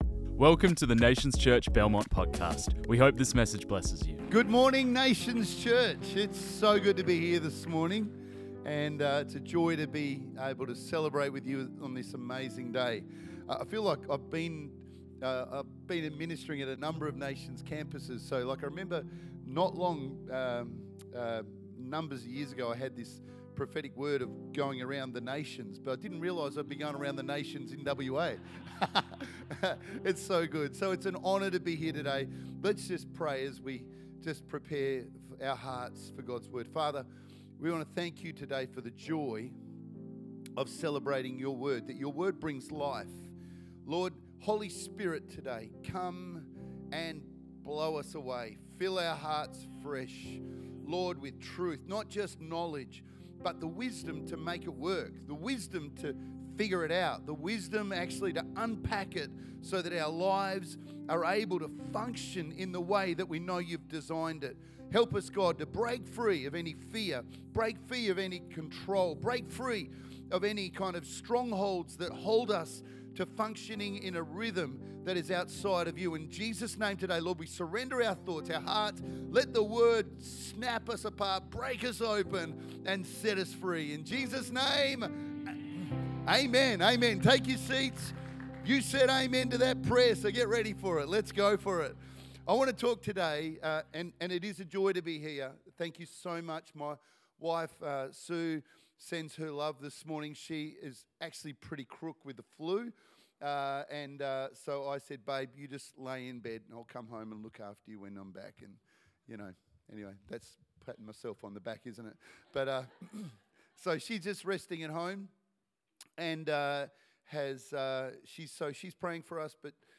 This message was preached on 06 July 2025.